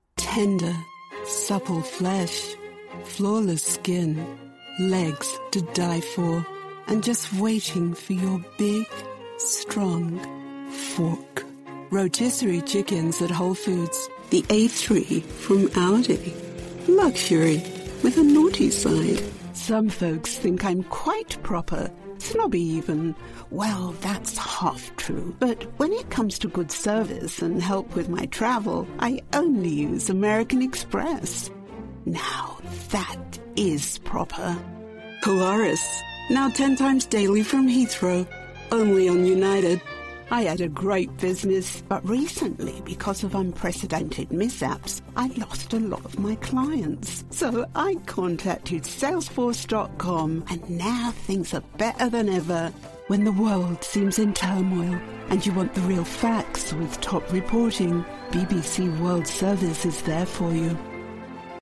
English (British)
Conversational
Warm
Friendly